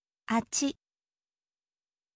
คำตัวอย่าง: あっち
atchi,อะ ชิ